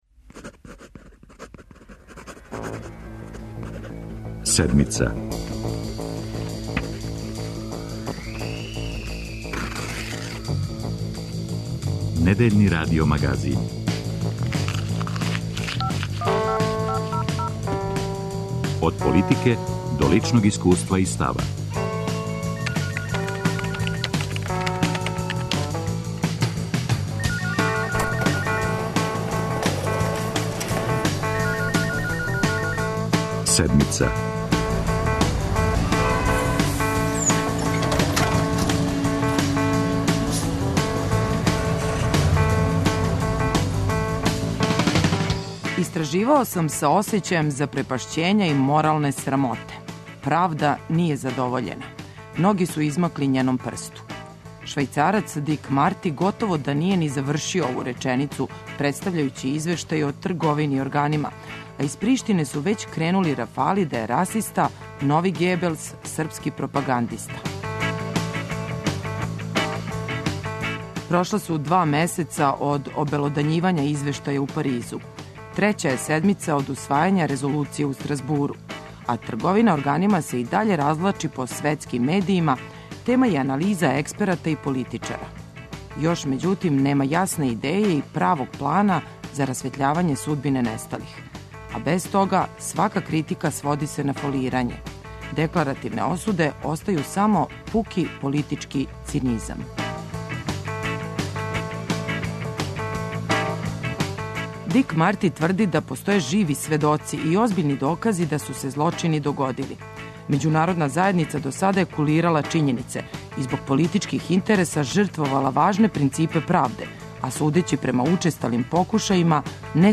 Радио Београд 1, 10:05.